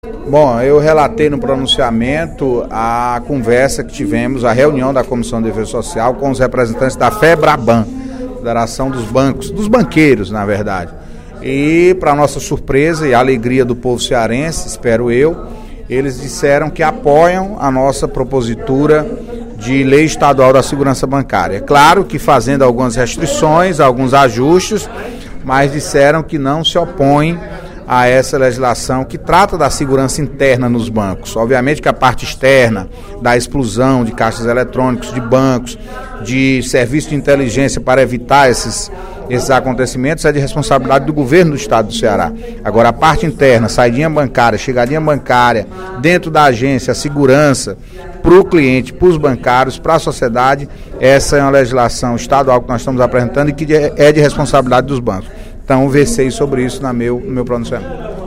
Durante o primeiro expediente da sessão plenária desta quarta-feira (23/10), o deputado Antonio Carlos (PT) anunciou o apoio da Federação Brasileira de Bancos (Febraban) ao projeto de lei nº 174/13, de sua autoria, que dispõe sobre a Lei Estadual da Segurança Bancária.